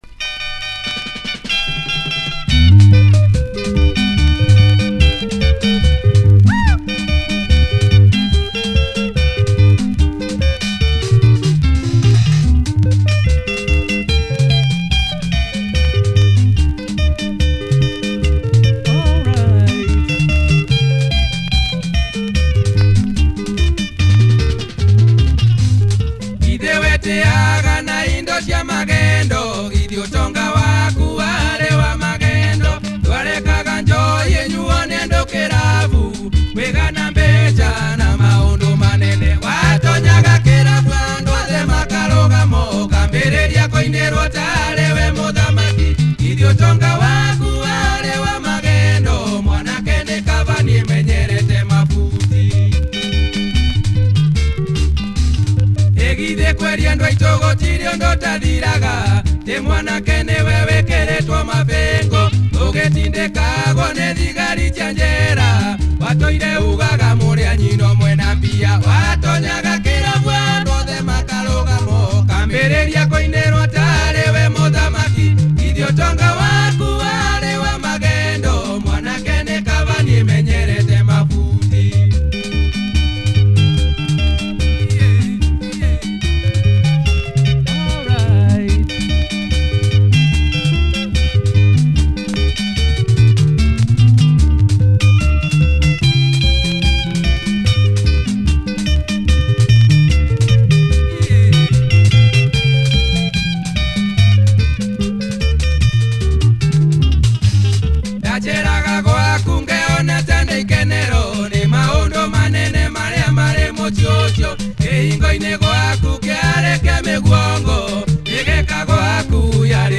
Nice Kikuyu Benga by this prolific Benga group. https